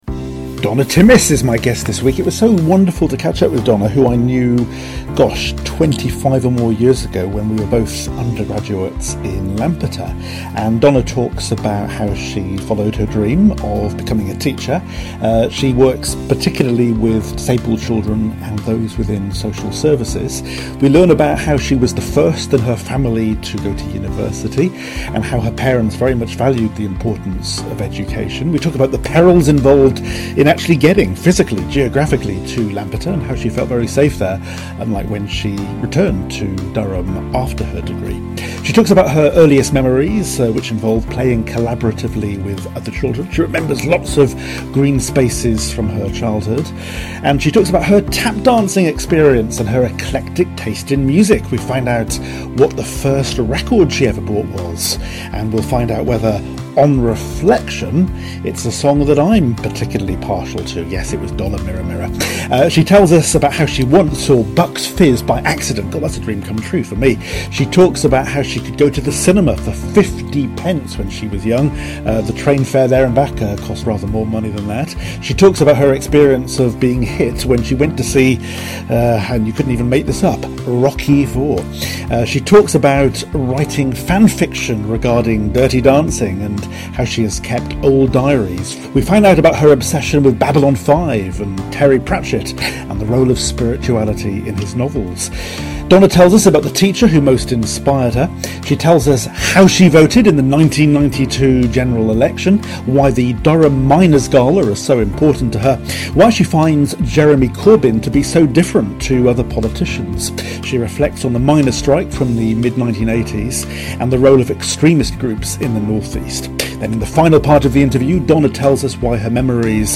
for my 200th Nostalgia Interview